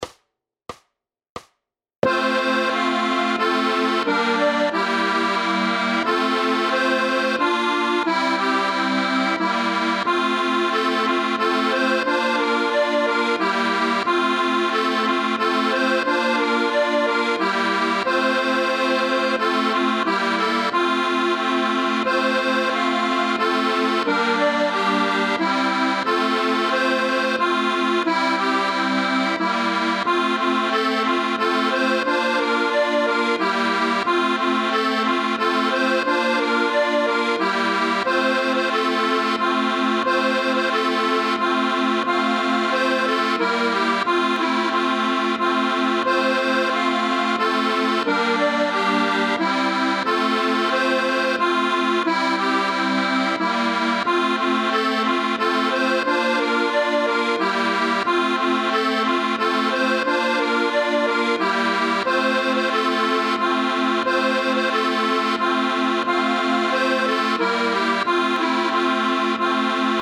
Formát Akordeonové album
Hudební žánr Vánoční písně, koledy